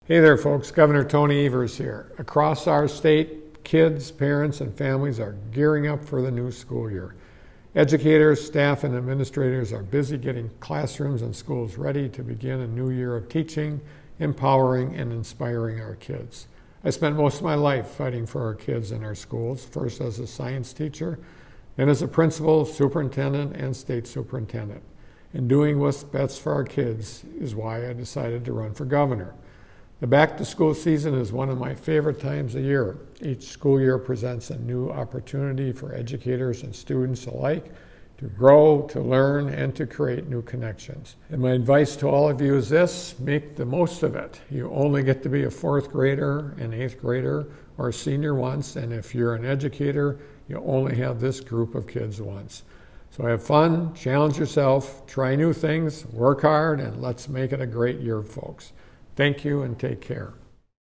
Weekly Dem radio address: Gov. Evers on welcoming Wisconsin students back to school - WisPolitics
MADISON — Gov. Tony Evers today delivered the Democratic Radio Address welcoming students, parents, families, educators, and school staff across Wisconsin back to school for the 2023-2024 school year.